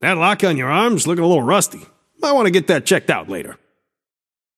Shopkeeper voice line - That lock on your arm is lookin‘ a little rusty. Might wanna get that checked out later.